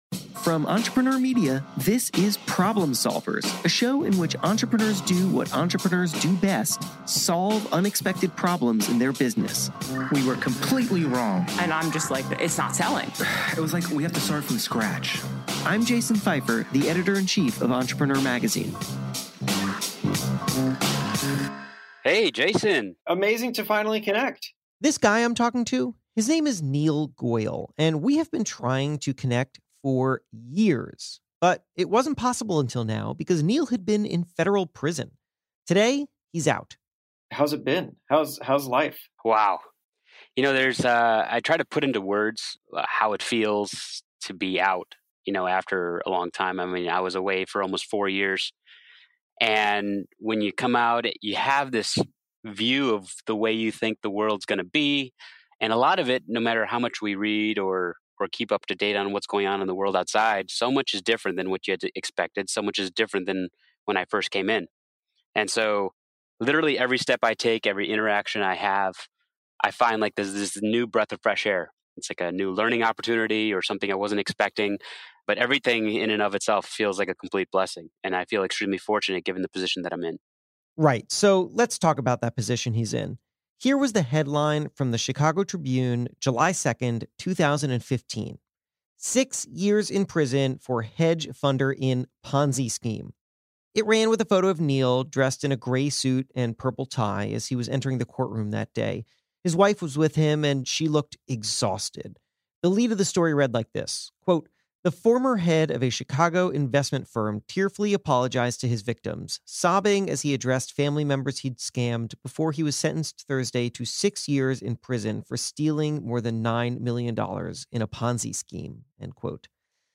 Today he’s out, and we have a frank conversation about what leads someone to make the kind of decisions he made, and the amount of soul searching and self-repair required to come back from it.